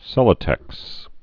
(sĕlə-tĕks)